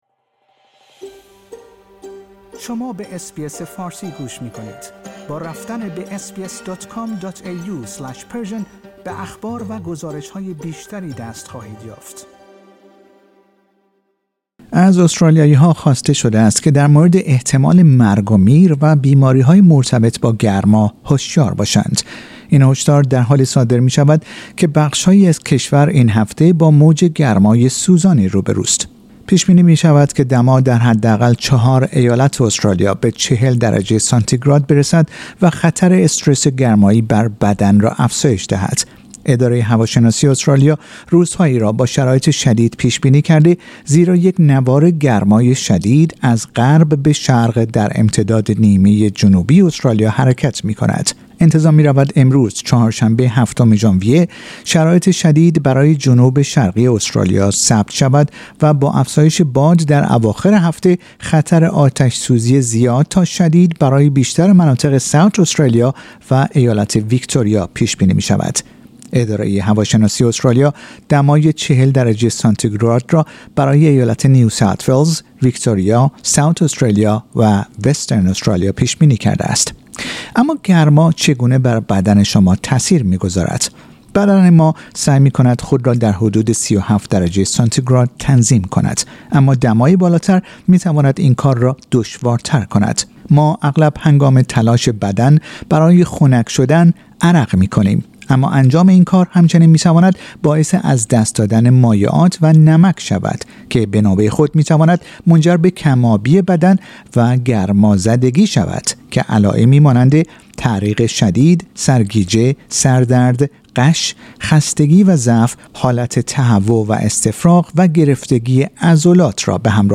همه افراد در مناطق تحت تأثیر موج گرما در معرض خطر هستند، حتی «جوانان سالم و سرحال». در این گزارش به نحوه تأثیر گرمای شدید بر بدن شما و نحوه ایمن ماندن پرداخته ایم.